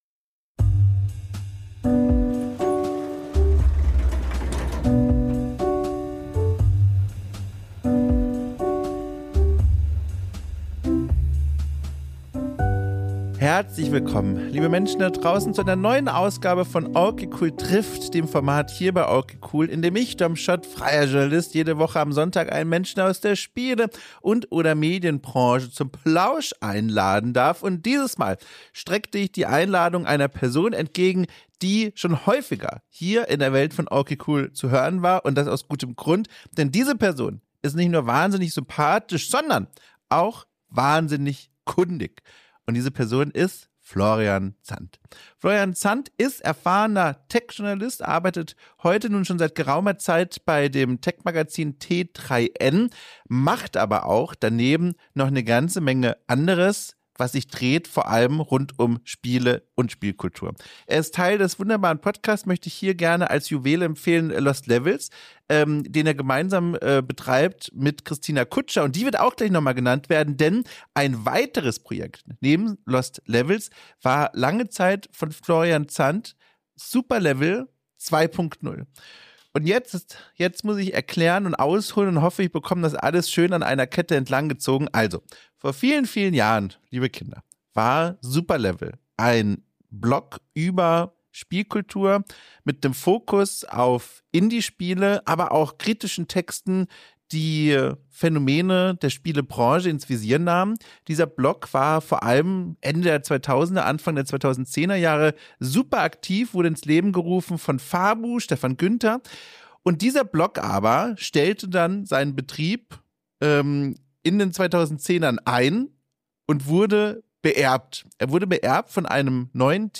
Gespräche über die Arbeit und das Leben drumrum